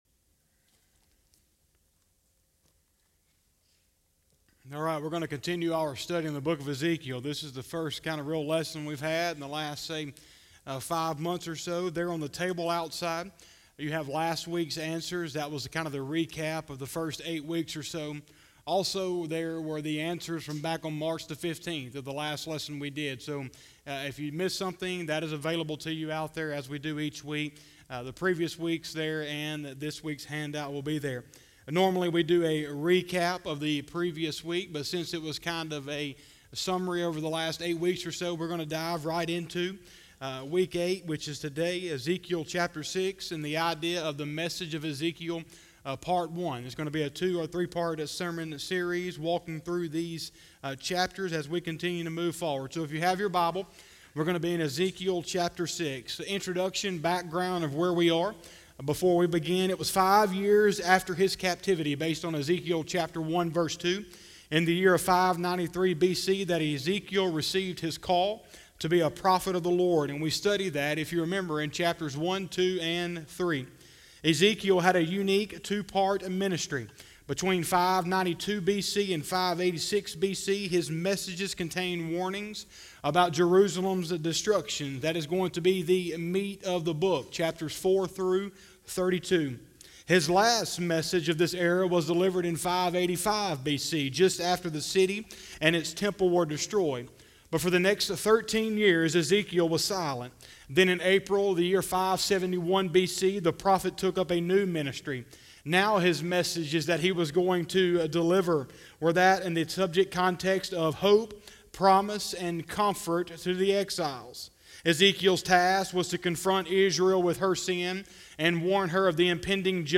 09/13/2020 – Sunday Evening Service